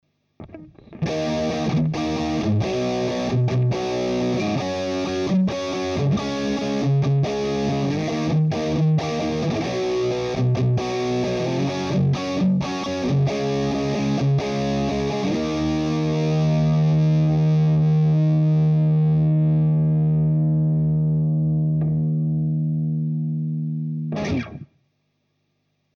Erster Profiling Test und ich kann nur sagen -> WOW!! Kaum ein Unterschied Hörbar, jetzt muss ich erstmal alle Amps mit allen möglichen Einstellungen profilen Anhänge Kemper Test - 0001 - Audio - orange.mp3 810,5 KB · Aufrufe: 167 Kemper Test - 0002 - Audio - kemper.mp3 810,5 KB · Aufrufe: 125